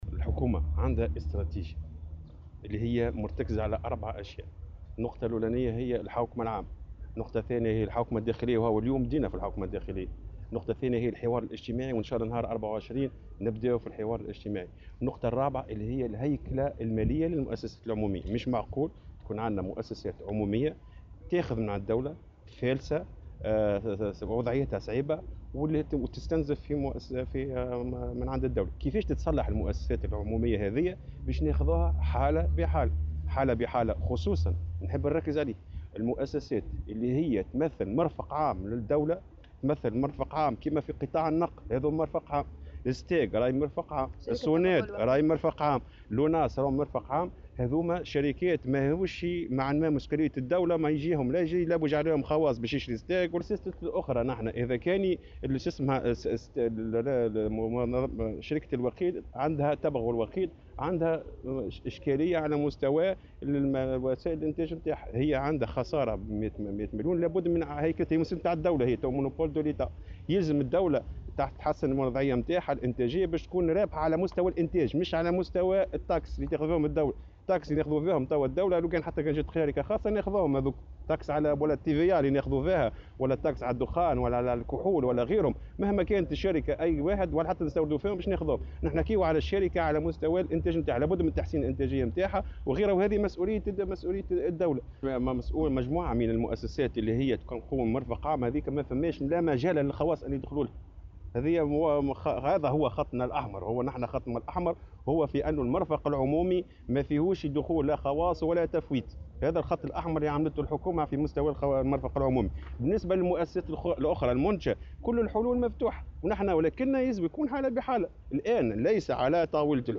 وأضاف في تصريح اليوم لمراسلة "الجوهرة أف أم" عل هامش ندوة تتعلق بالشروع في تنفيذ مخطط حوكمة المنشآت و المؤسسات العمومية، أنه لا مجال للتفويت في المؤسسات التي تعتبر منشآت عمومية على غرار "الستاغ" و"الصوناد" وغيرها، مشيرا إلى أن استراتيجية الحكومة لاصلاح هذه المؤسسات ترتكز على 4 محاور وهي الحوكمة العامة والحوكمة الداخلية والحوار الاجتماعي وإعادة الهيكلة.